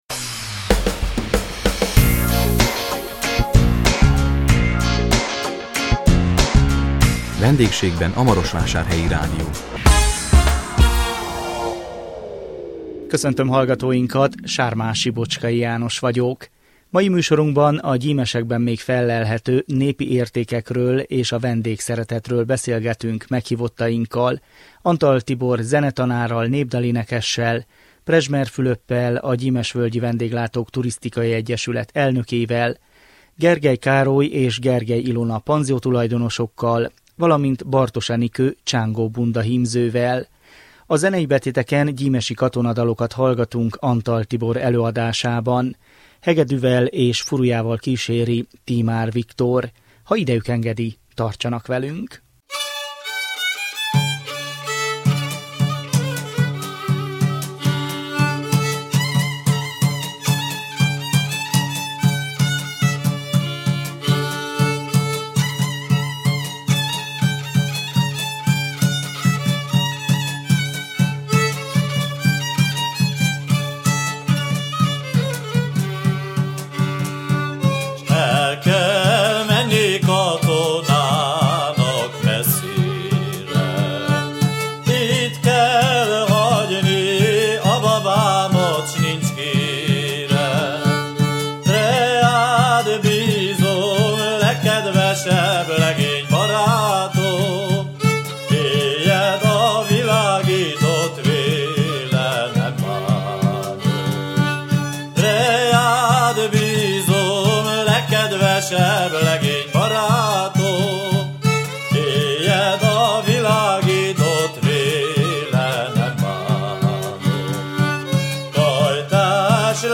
A 2017 május 4-én jelentkező Vendégségben a Marosvásárhelyi Rádió című műsorunkban a Gyimesekben még fellelhető népi értékekről és a vendégszeretetről beszélgettünk meghívottainkkal